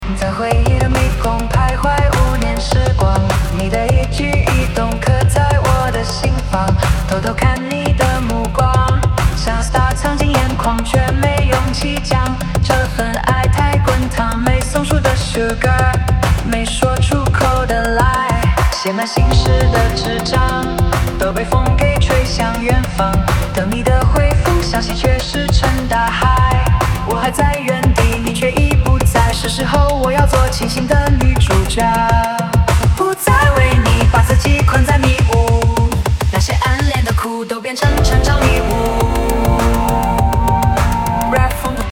rap风格
人工智能生成式歌曲